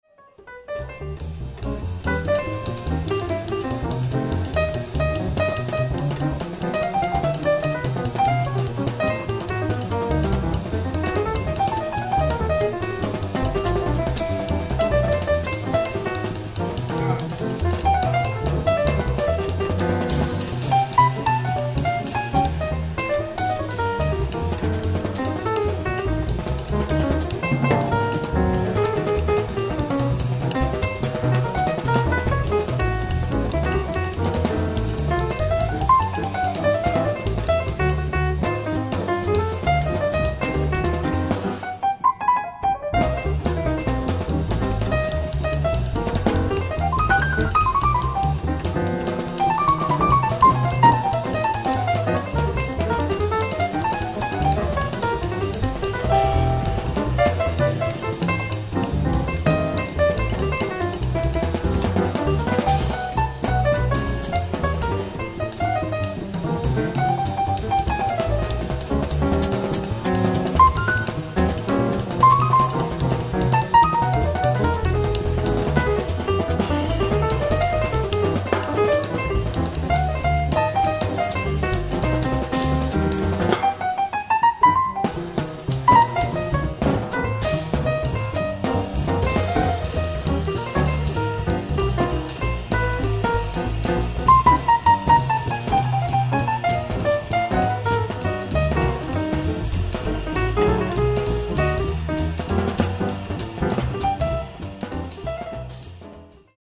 ライブ・アット・ザ・ギルモア・ピアノ・フェスティバル、カラマズー、ミシガン 04/13/2025
ステレオ・サウンドボード収録！！
※試聴用に実際より音質を落としています。